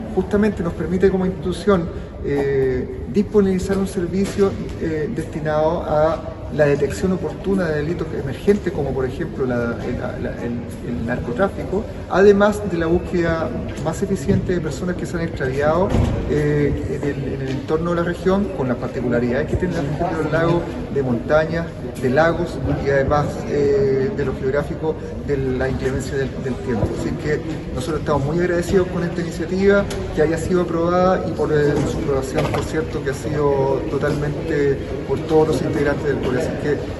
El general de la Décima Zona de Carabineros Los Lagos, Héctor Valdez, agregó que el equipamiento irá a un directo beneficio a la ciudadanía, porque permite a la institución tener un servicio destinado a la detección oportuna de delitos emergentes, como por ejemplo el narcotráfico, además de la búsqueda más eficiente de personas que se han extraviado en la región, entre otras.